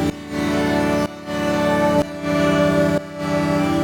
GnS_Pad-MiscB1:2_125-E.wav